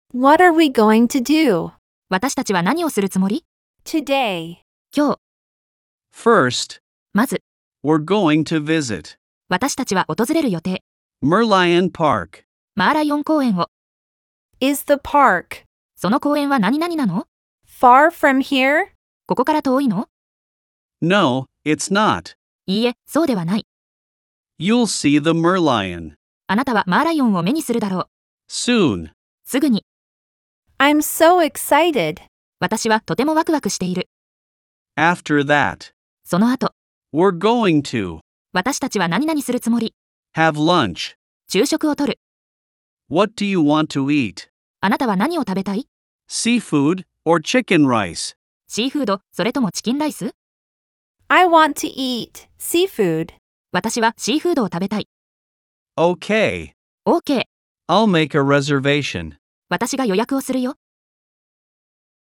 ♪ 習得用の音声(英⇒日を区切りごと)：